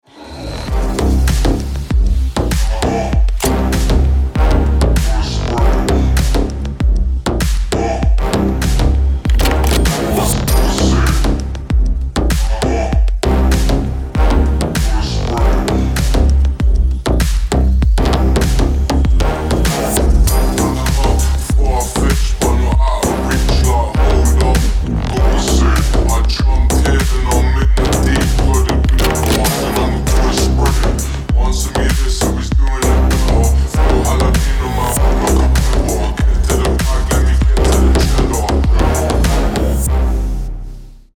• Качество: 320, Stereo
мощные
Electronic
басы
G-House
грозные
Стиль: g-house